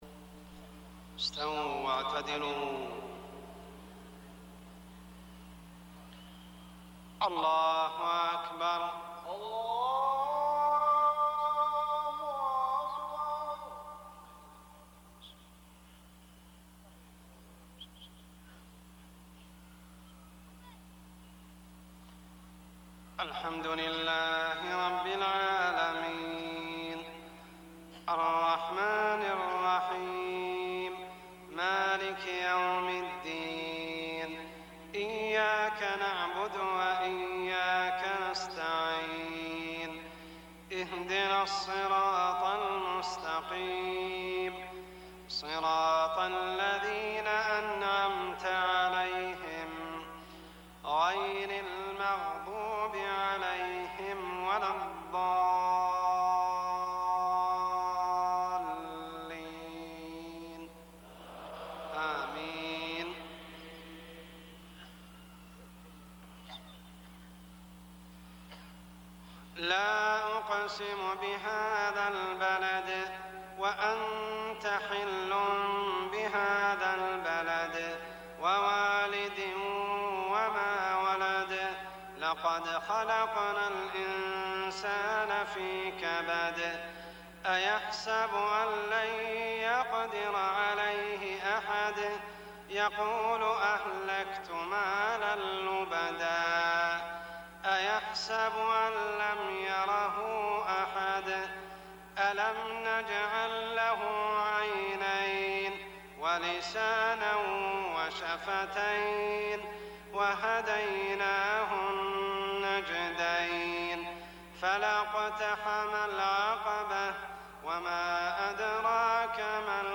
صلاة العشاء عام 1420هـ سورتي البلد و التين > 1420 🕋 > الفروض - تلاوات الحرمين